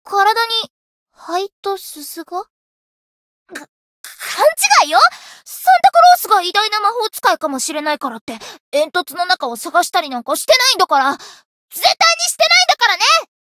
灵魂潮汐-神纳木弁天-圣诞节（摸头语音）.ogg